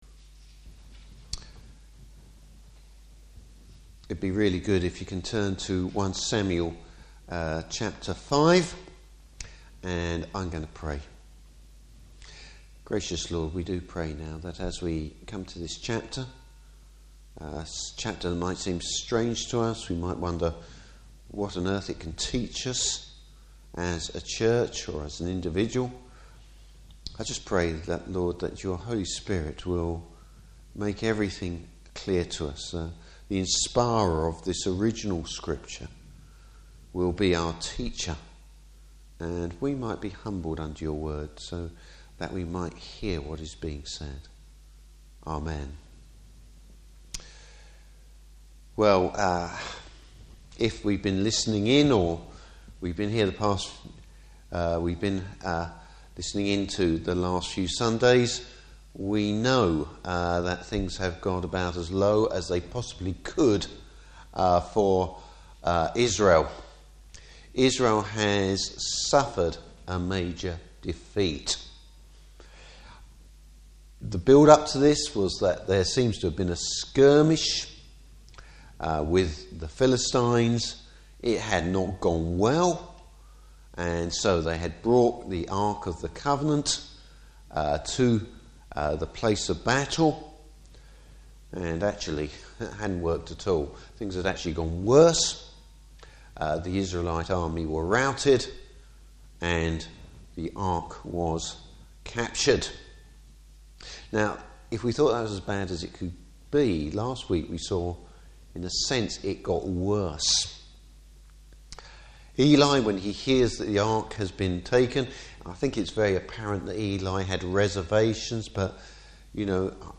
Passage: 1 Samuel 5. Service Type: Evening Service Don’t mess with the Lord God Almighty!